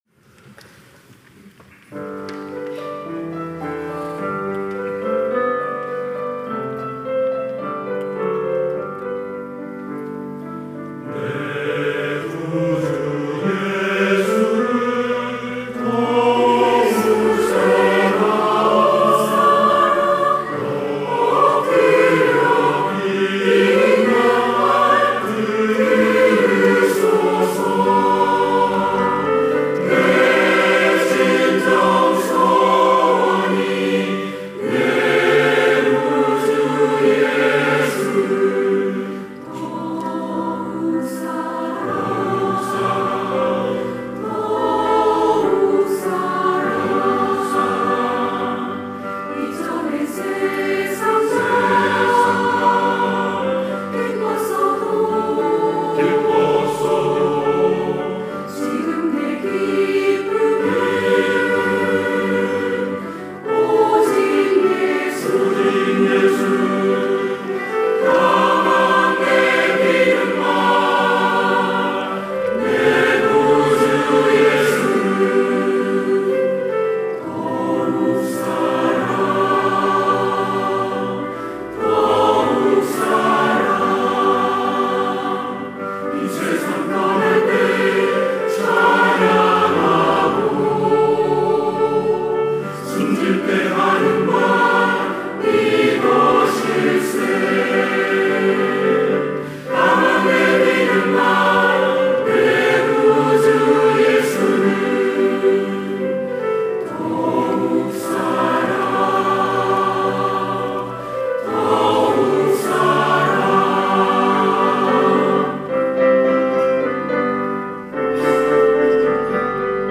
시온(주일1부) - 내 구주 예수를 더욱 사랑
찬양대